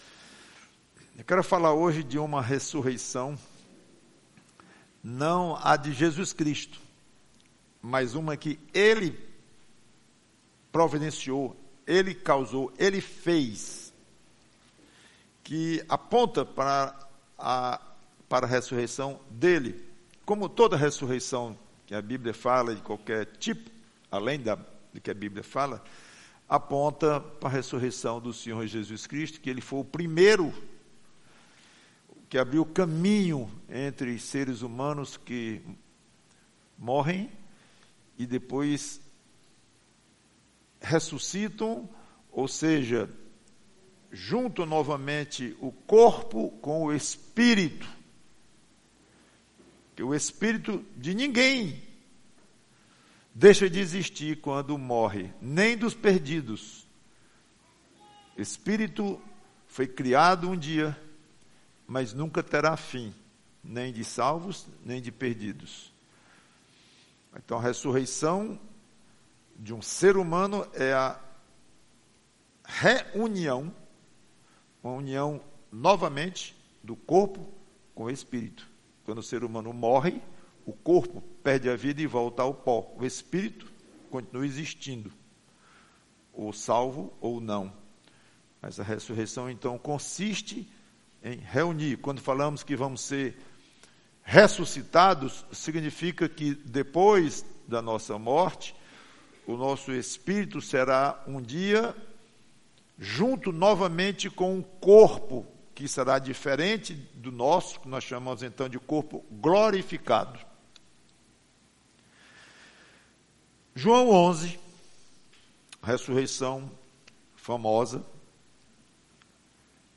PREGAÇÃO Lázaro, vem para fora!